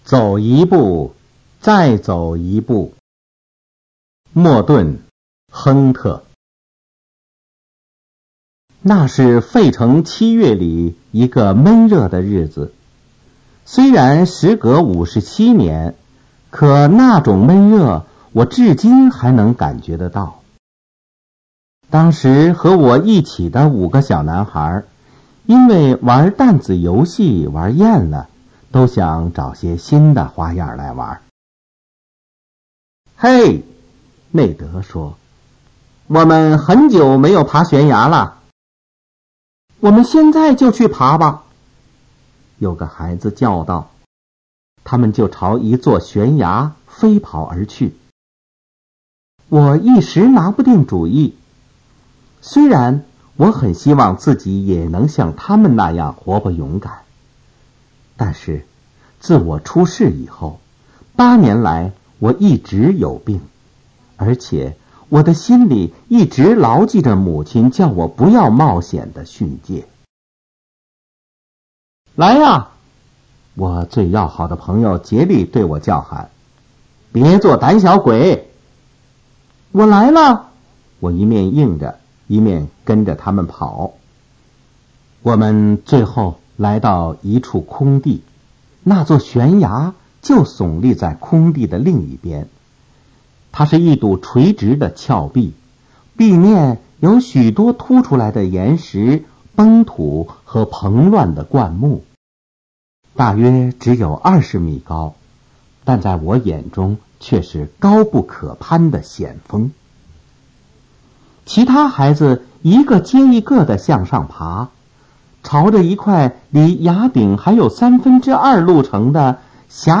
《走一步，再走一步》影音资料(男声朗读)